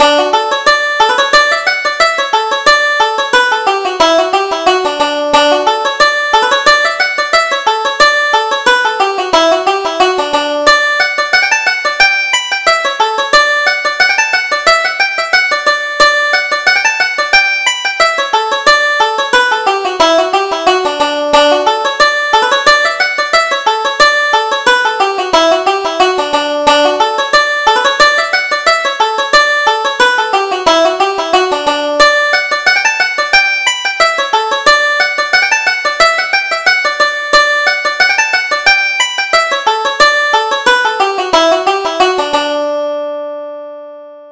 Reel: Don't Bother Me